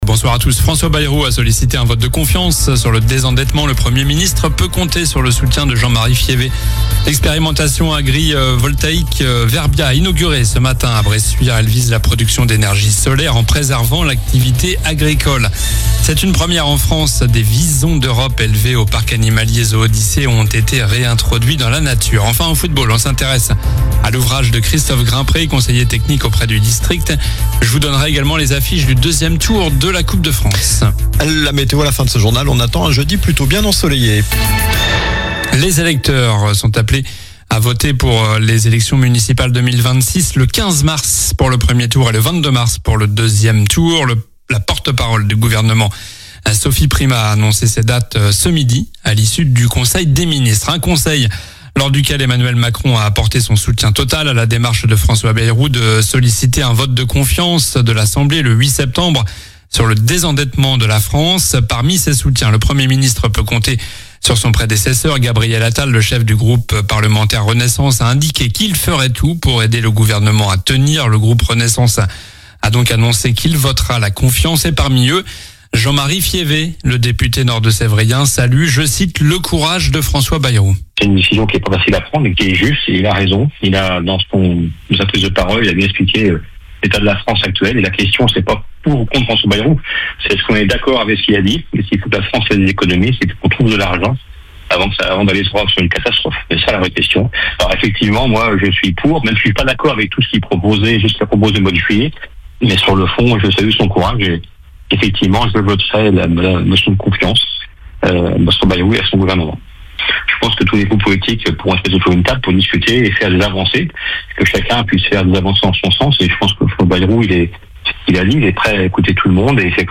Journal du mercredi 27 août (soir)